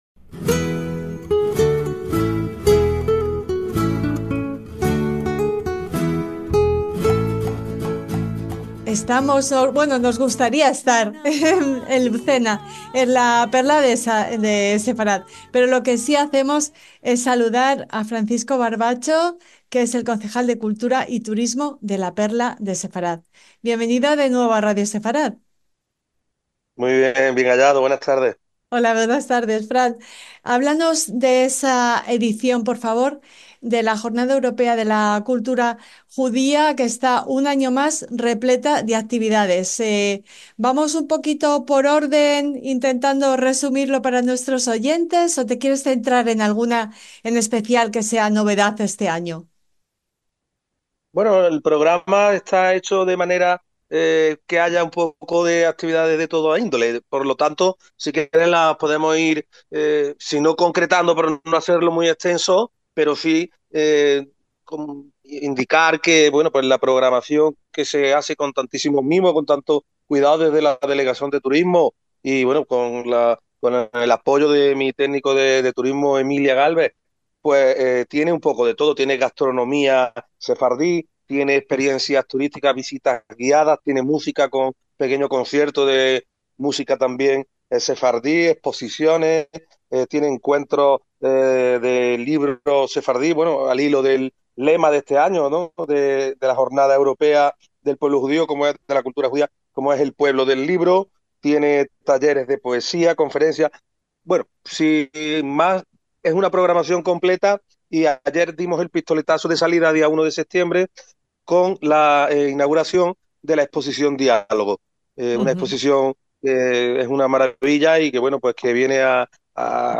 El concejal de Cultura y Turismo Francisco Barbancho nos pasea por Lucena y nos invita a más de una decena de actividades culturales que combinan patrimonio, música, gastronomía, literatura y teatro.